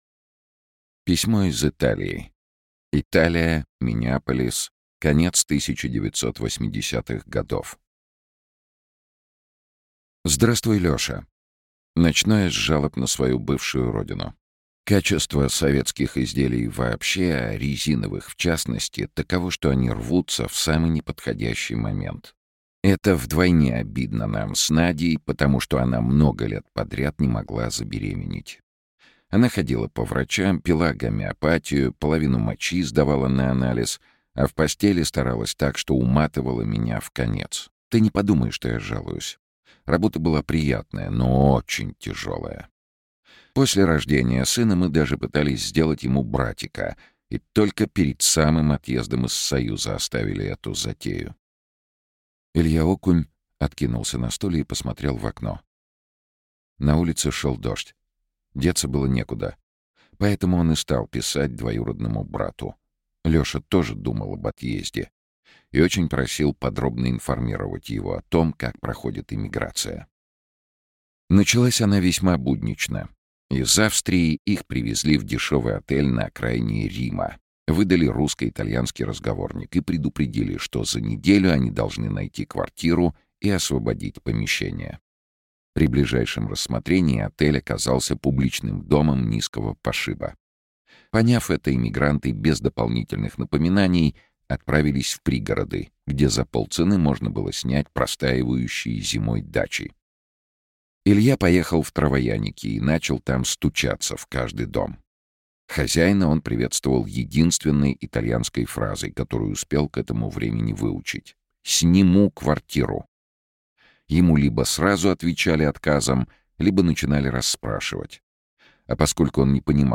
Аудиокнига Письмо из Италии | Библиотека аудиокниг
Aудиокнига Письмо из Италии Автор Владимир Владмели Читает аудиокнигу Сергей Чонишвили.